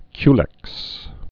(kylĕks)